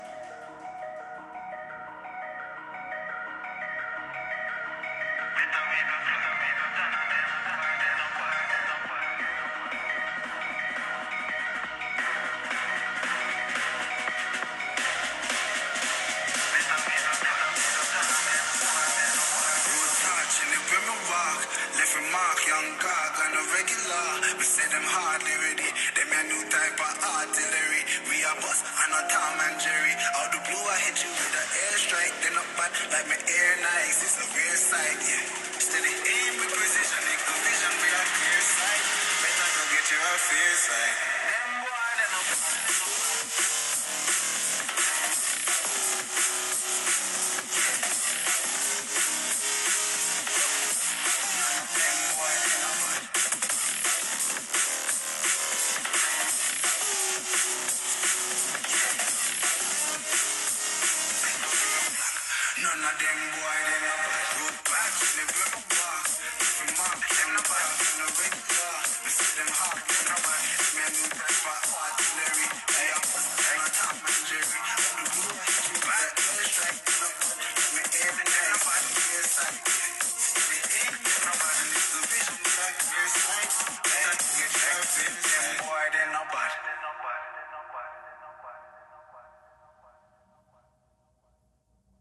Zenfone 9には上部・下部それぞれにスピーカーが搭載されているので、横持ちならステレオサウンドを楽しむことができちゃいます。
実際にZenfone 9のスピーカーから流れる音を録音してみたので参考に聞いてみてください。
これは僕が持っているスマホの中で最も音質が高いと感じているiPhone 14 Proで同じ音源を流している音です。
比べてみるとiPhne 14 Proよりも音の広がりが狭く感じました。また、最大音量もZenfone 9の方が小さいようです。
zenfone-9-iphone-14-speaker.mp3